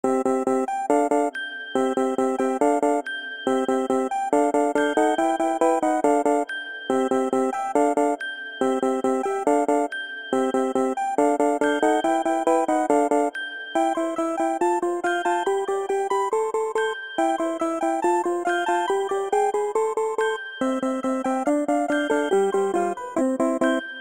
zuk-electric-bell_24605.mp3